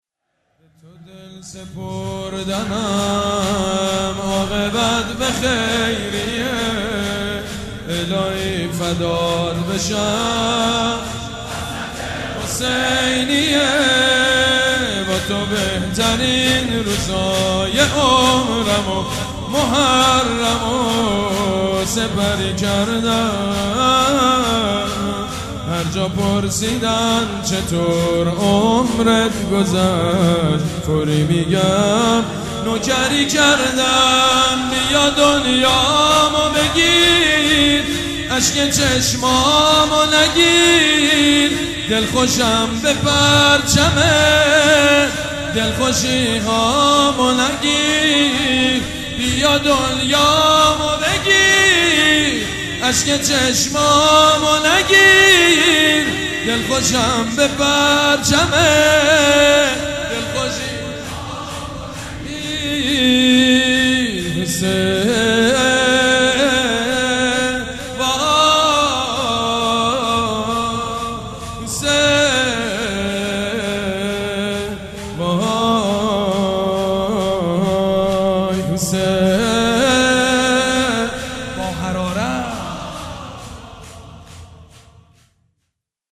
مـراسـم سیاه پوشان
شور
مداح
حاج سید مجید بنی فاطمه